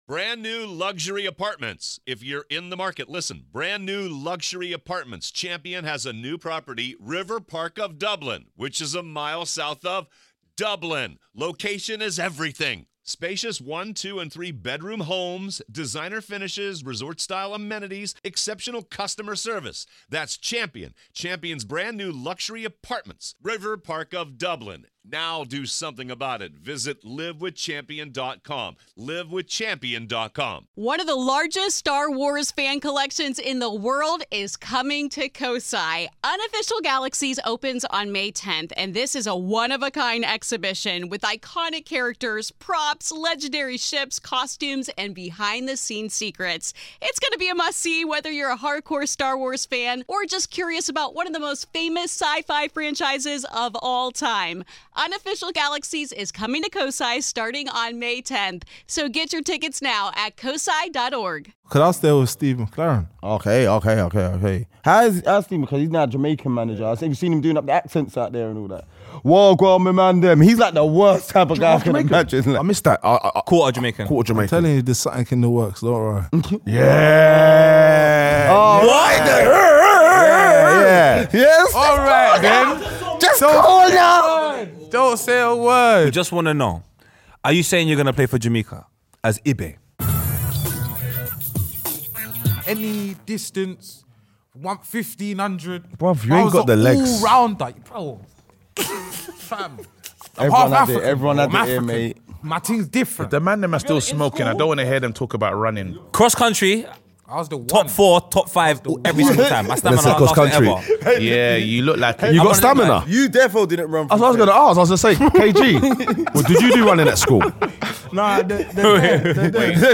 FILTHY WELCOME A FORMER LIVERPOOL, BOURNEMOUTH AND NOW HUNGERFORD TOWN WINGER, JORDAN IBE, TO THE TABLE FOR A CONVERSATION THAT CANNOT BE MISSED!